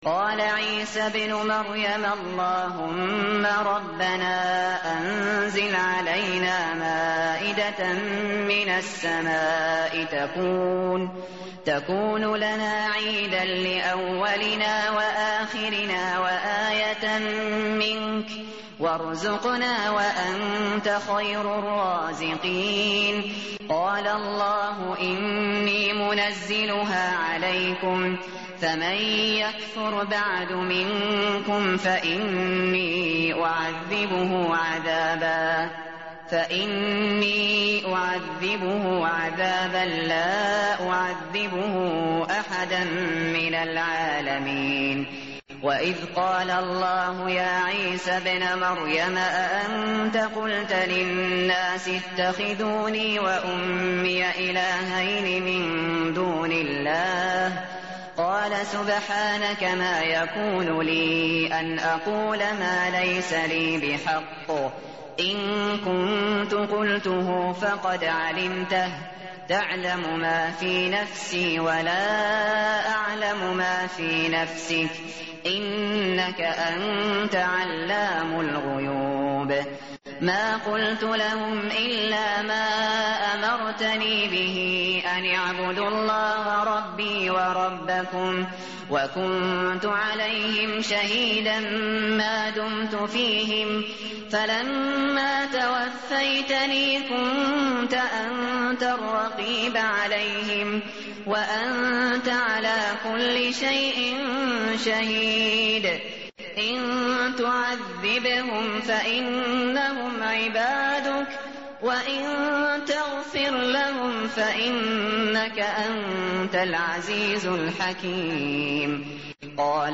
tartil_shateri_page_127.mp3